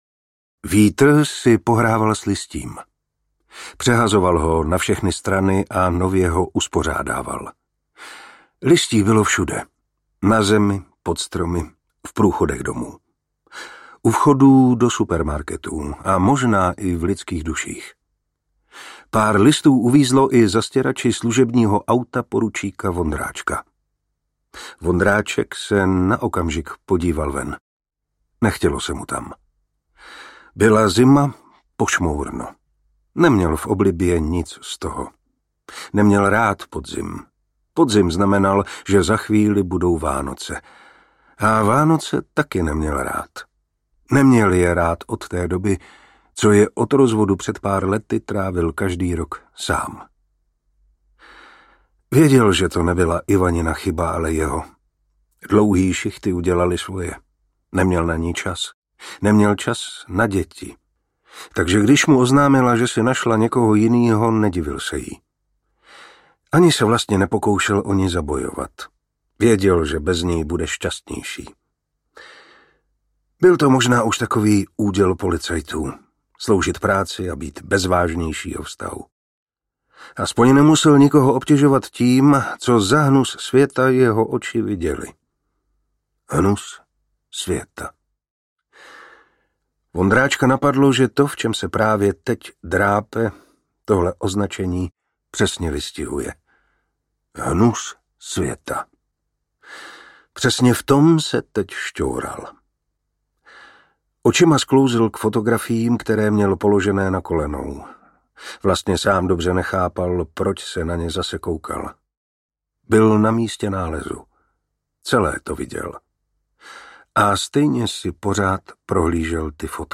V temnotách audiokniha
Ukázka z knihy
• InterpretMartin Preiss